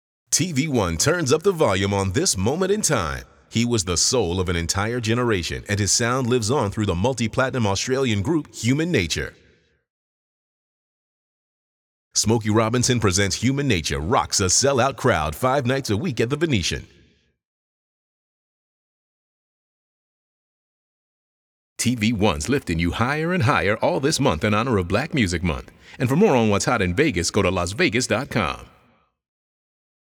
HumanNature_MomentinTime_VO.wav